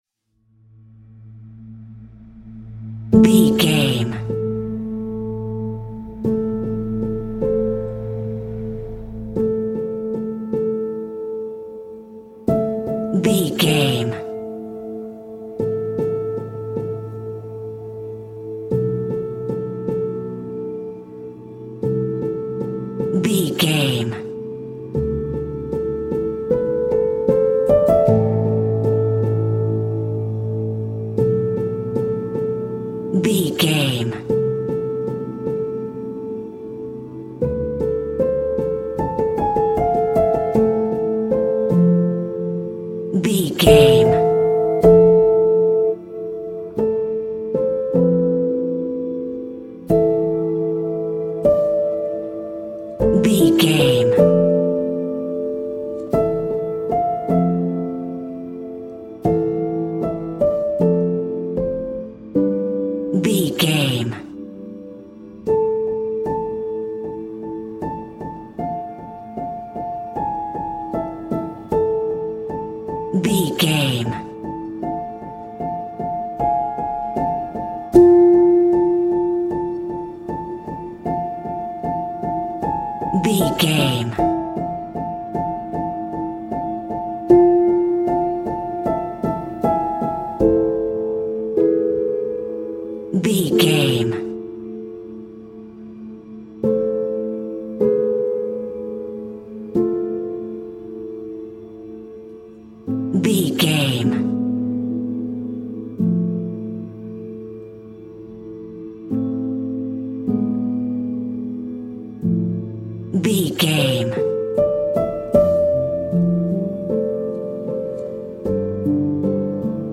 Aeolian/Minor
tension
suspense
dark
piano
synthesiser